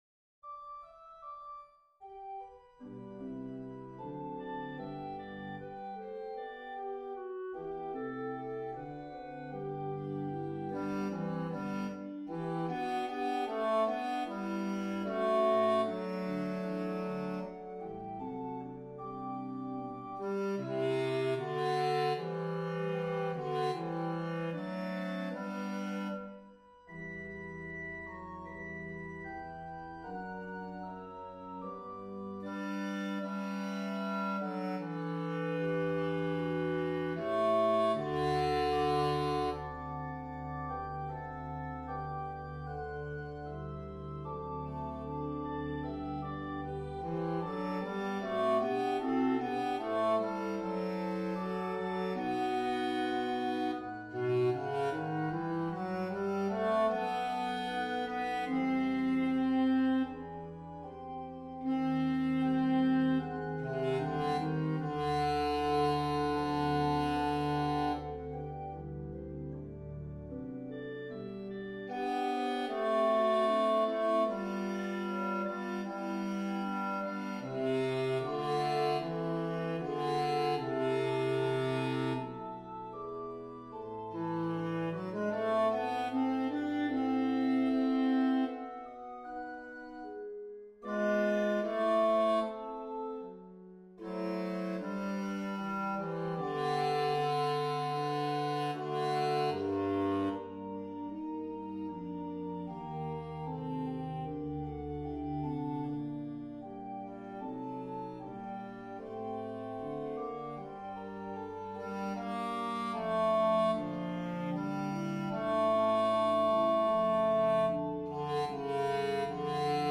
How-beauteous-are-their-feet-RR-Bass.mp3